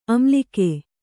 ♪ amlike